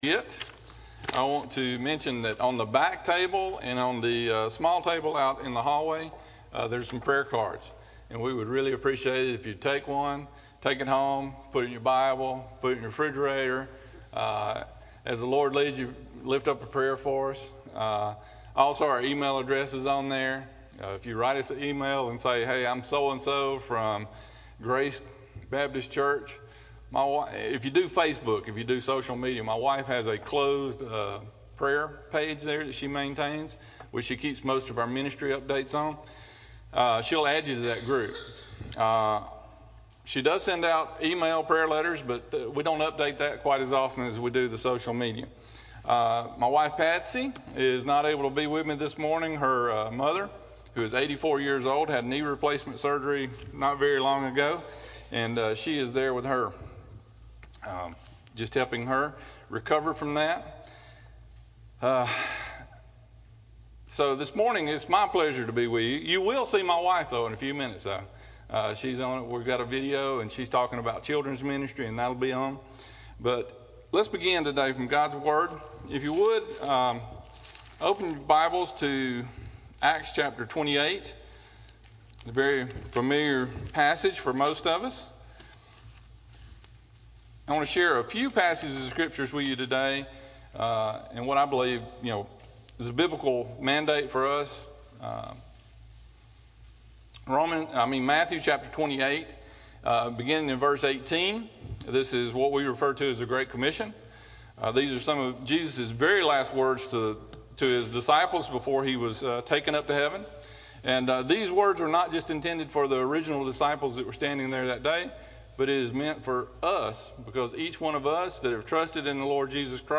01-sermon-9-22-19-CD.mp3